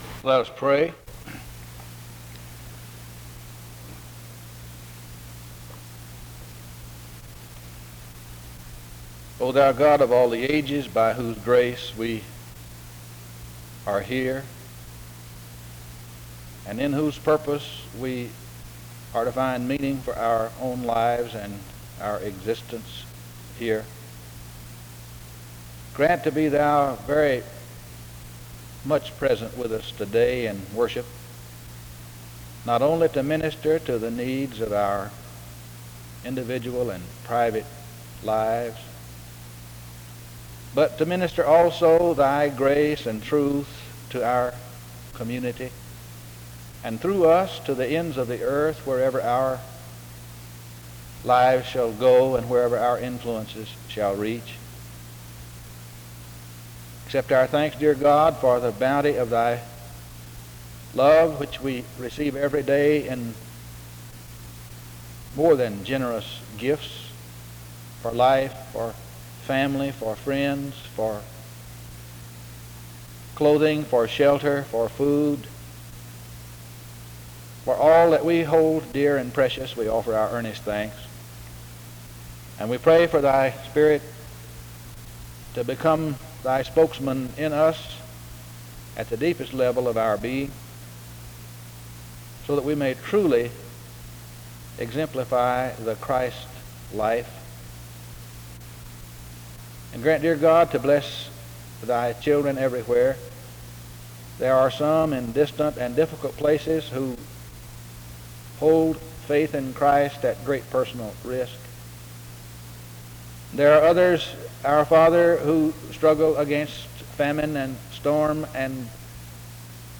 The service begins with prayer from 0:00-3:16.
Closing music plays from 14:03-15:04. The service closes in prayer from 15:20-15:35.
SEBTS Chapel and Special Event Recordings SEBTS Chapel and Special Event Recordings